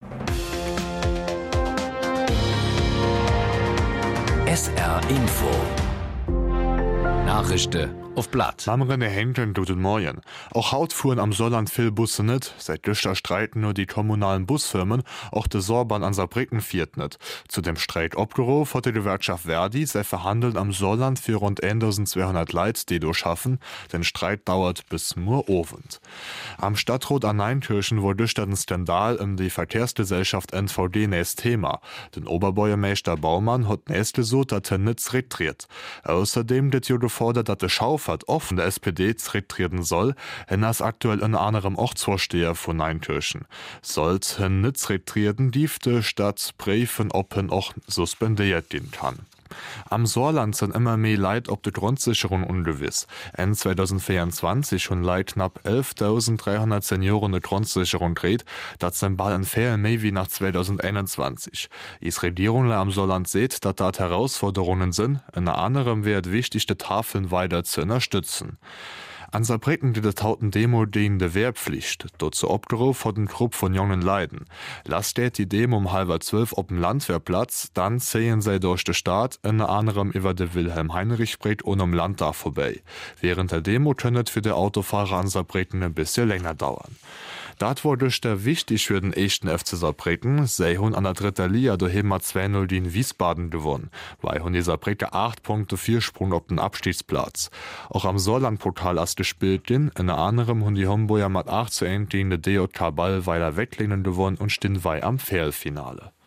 Von Montag bis Freitag gibt es bei SR 3 Saarlandwelle täglich um 08:30 Uhr Schlagzeilen in Mundart. Die "Nachrischde uff platt" werden mal in moselfränkischer, mal in rheinfränkischer Mundart präsentiert.